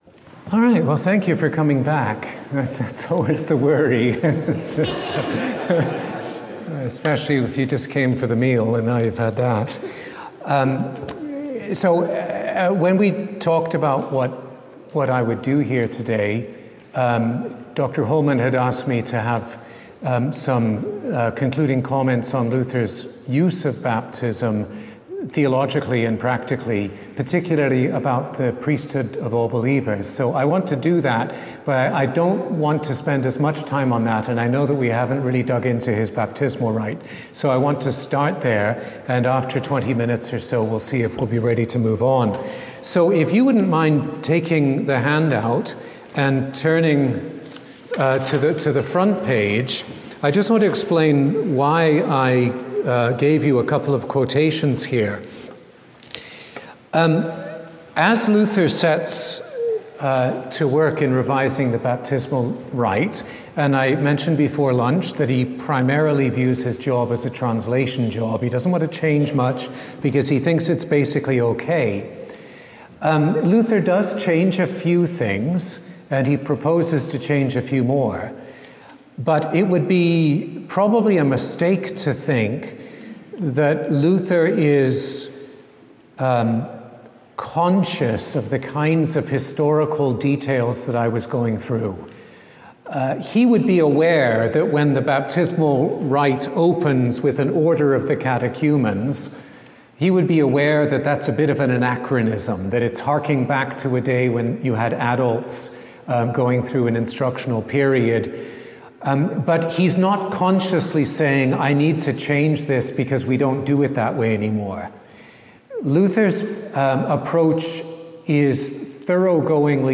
A lecture
to the inaugural Confessio conference at Concordia University, St. Paul, MN